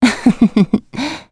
Gremory-Vox_Happy1.wav